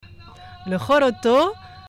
Rolloto play all stop uitspraak Rolloto.